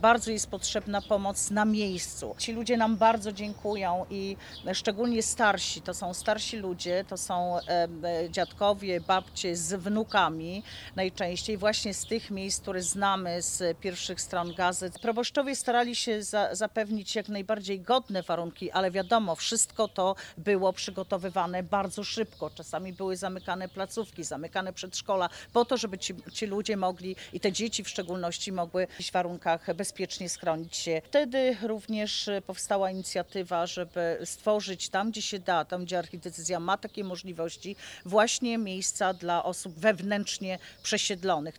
W inicjatywę zaangażowała się także europoseł Beata Kempa, która podziękowała wszystkim zaangażowanym w pomoc dla Ukrainy.